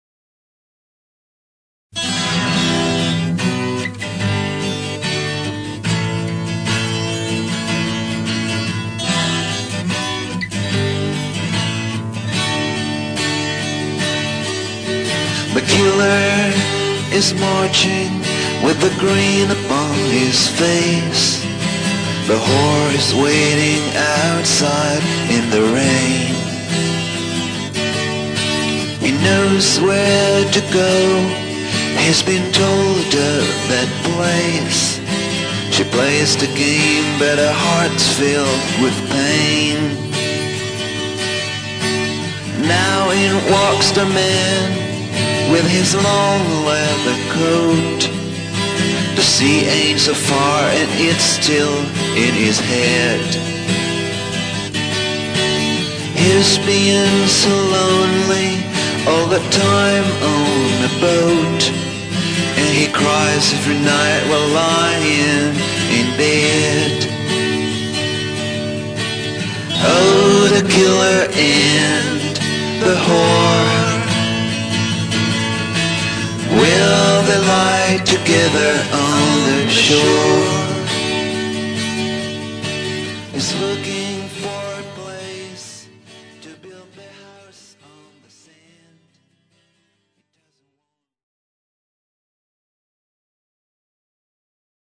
Maquettes et "alternate takes" de l'album